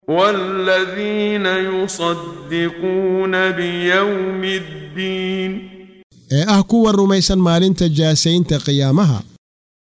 Waa Akhrin Codeed Af Soomaali ah ee Macaanida Suuradda A-Macaarij ( Wadooyinka samada ) oo u kala Qaybsan Aayado ahaan ayna la Socoto Akhrinta Qaariga Sheekh Muxammad Siddiiq Al-Manshaawi.